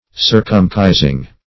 circumcising - definition of circumcising - synonyms, pronunciation, spelling from Free Dictionary
Circumcise \Cir"cum*cise\, v. t. [imp. & p. p. Circumcised; p.